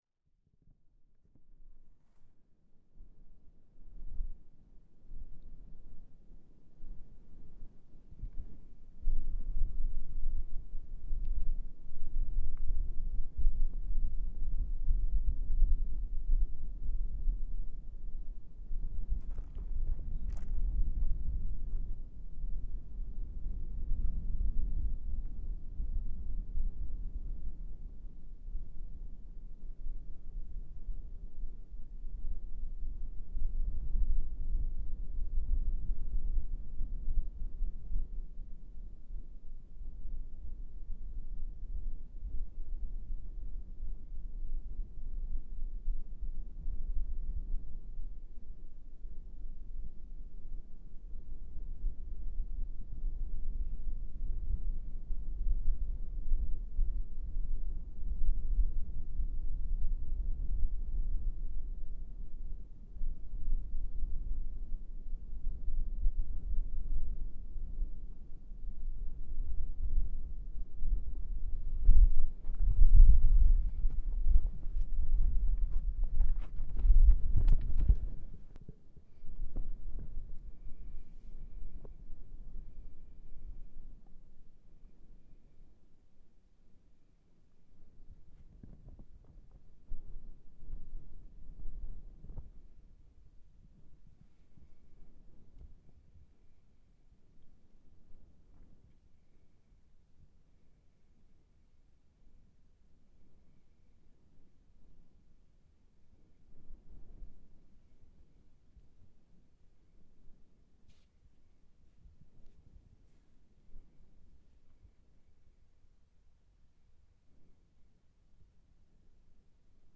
Sounds recorded at a stunning natural feature called The Edge of the World just outside Riyadh, where the land ends in an abrupt 350-metre cliff, with the horizon extending out into nothing but desert as far as the eye can see.